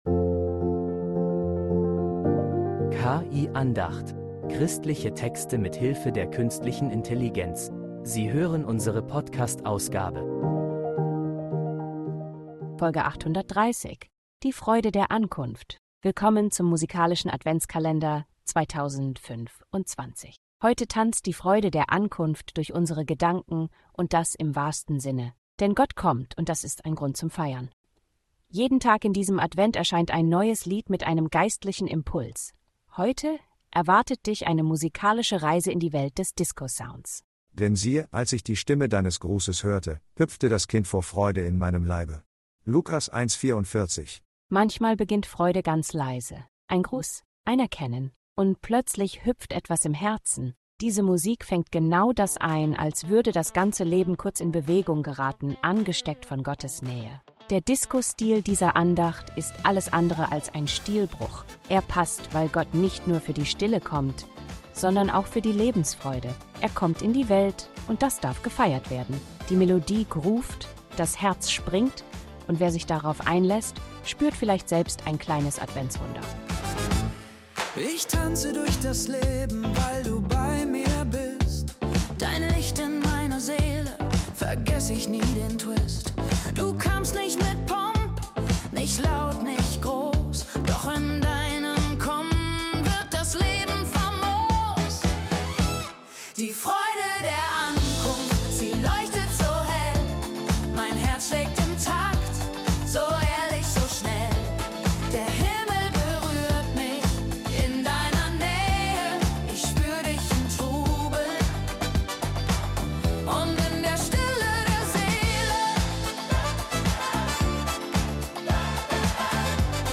Ein ungewöhnlicher Soundtrack für ein himmlisches Ereignis
Ankunft Gottes gefeiert – mit Beat, Groove und guter Nachricht.